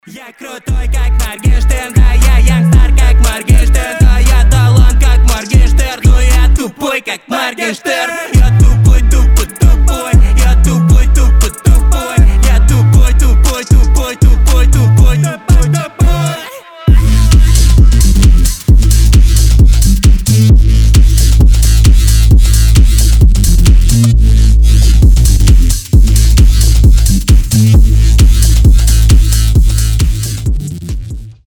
• Качество: 320, Stereo
жесткие
веселые
качающие
грубые
breakbeat